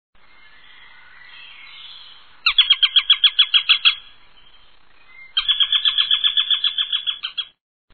Eurasian Sparrowhawk
Eurasian-Sparrowhawk.mp3